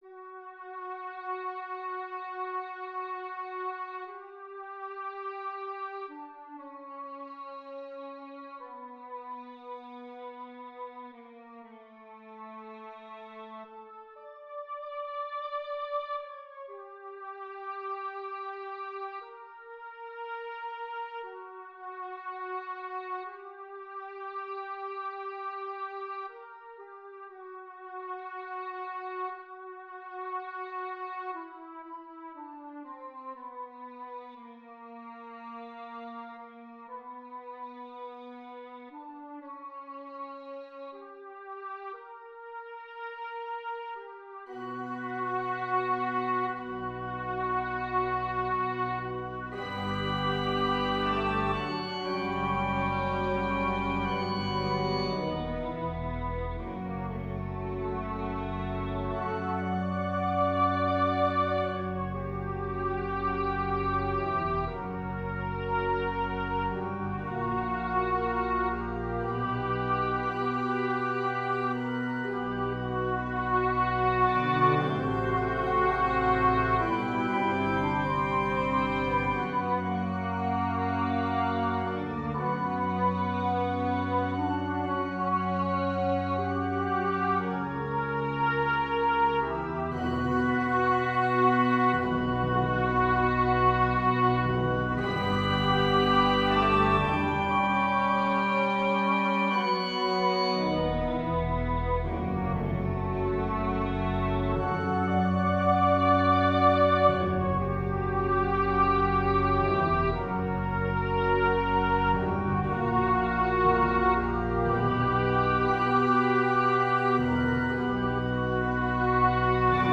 Het zijn toon schetsen.